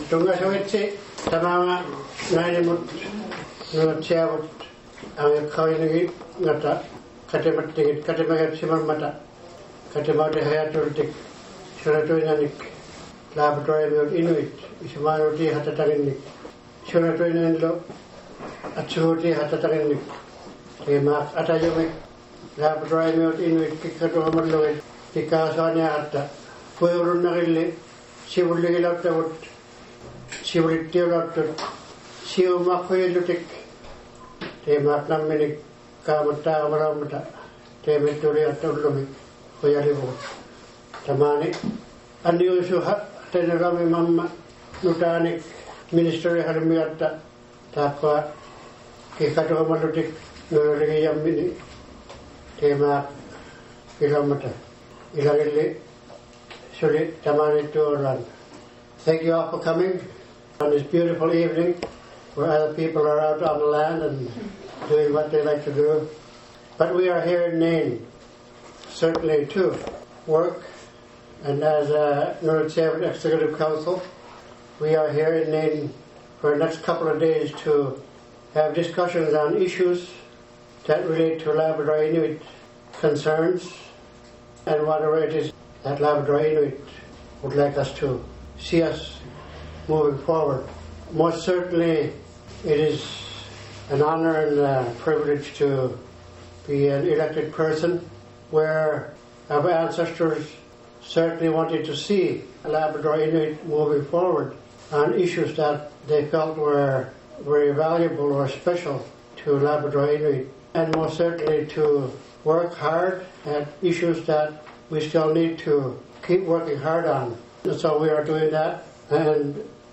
The Nunatsiavut Executive Council had their Open House last evening at the Nunatsiavut Government building boardroom.
President Johannes Lampe started off by welcoming everyone.
Then, each minister reported on their departments.